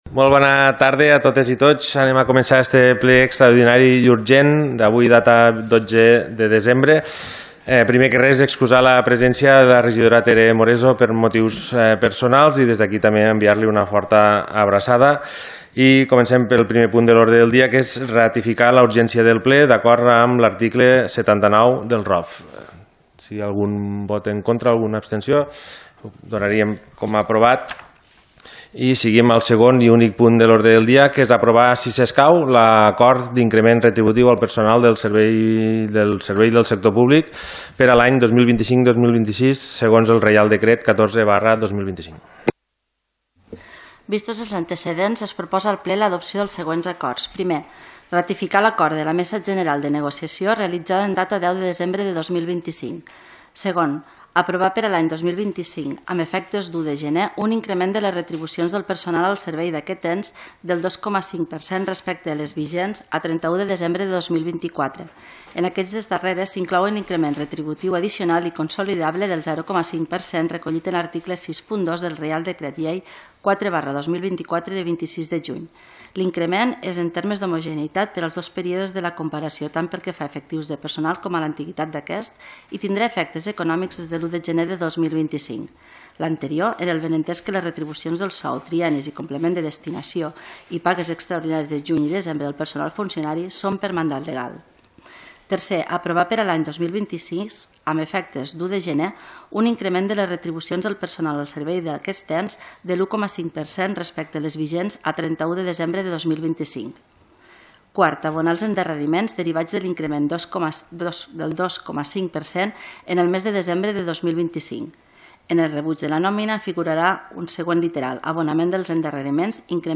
Aquest divendres 12 de desembre s’ha celebrat a la sala de sessions de l’Ajuntament de Roquetes el Ple extraordinari i urgent número 16/2025, corresponent al mes de desembre.